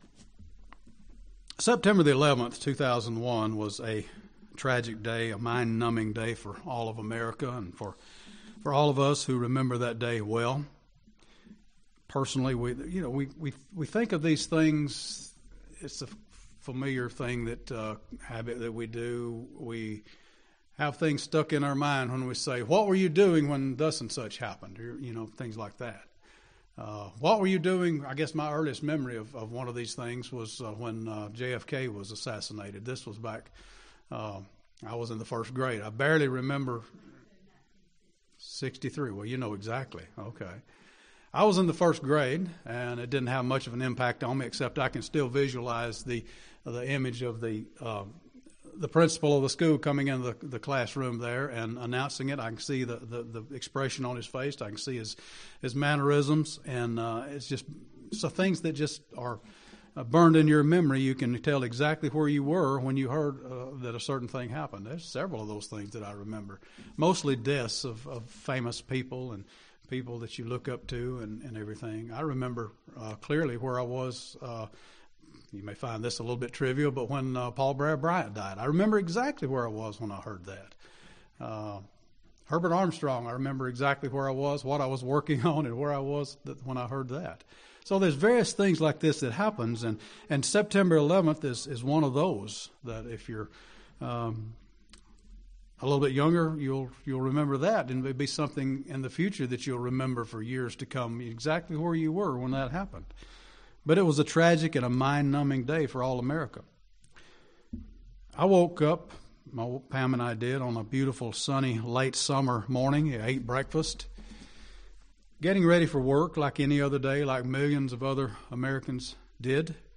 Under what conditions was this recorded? Given in Birmingham, AL Gadsden, AL